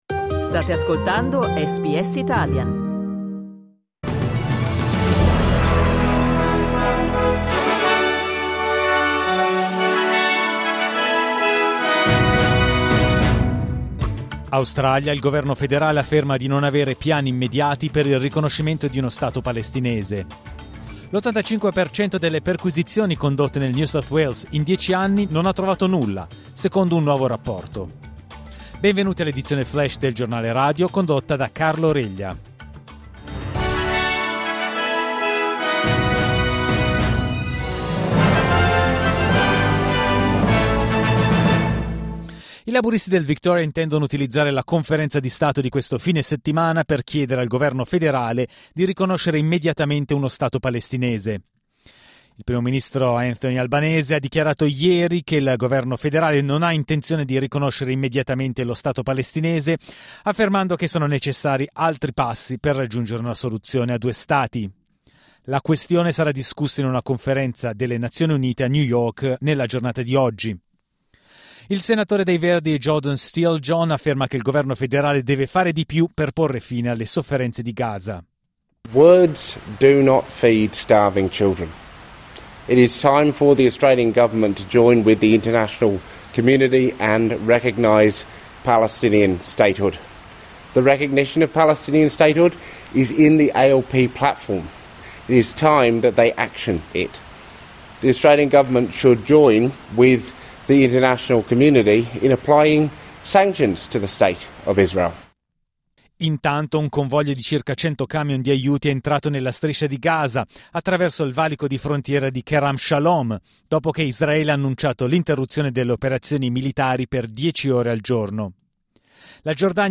News flash lunedì 28 luglio 2025